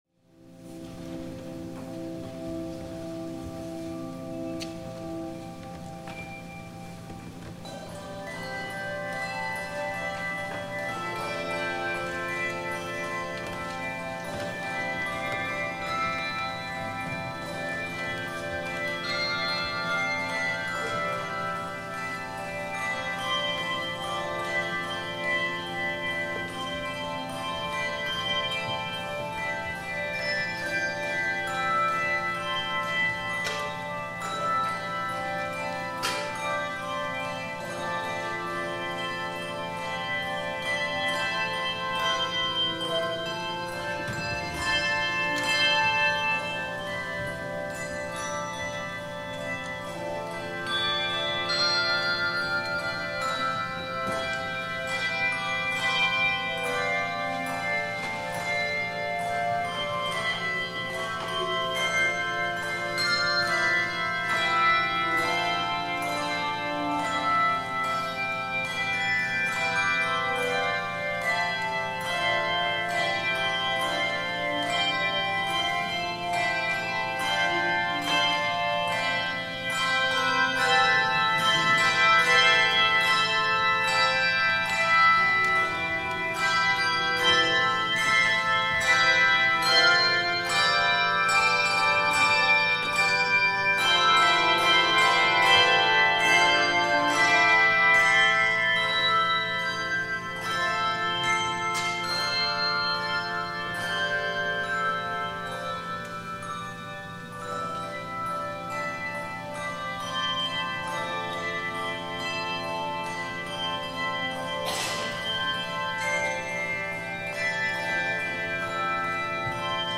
THE OFFERTORY
Morningside Handbell Choir